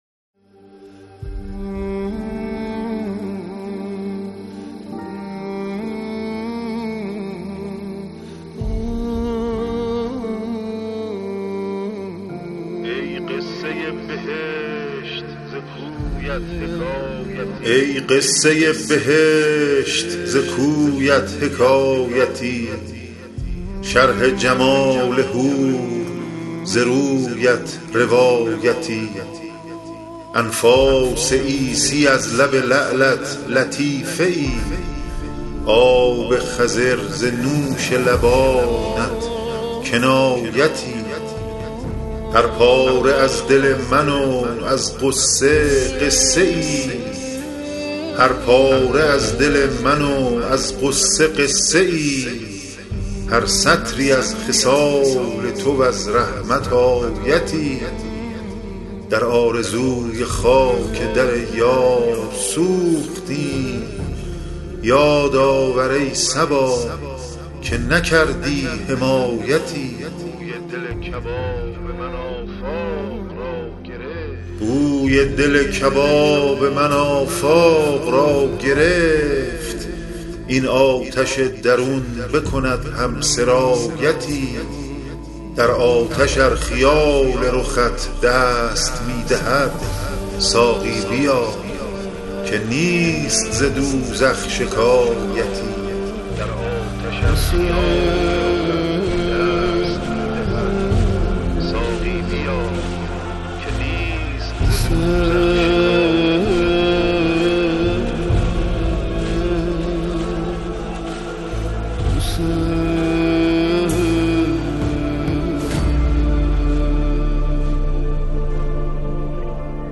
✦ پژوهشگر و راوی
✦ نویسنده و گوینده متن‌های ادبی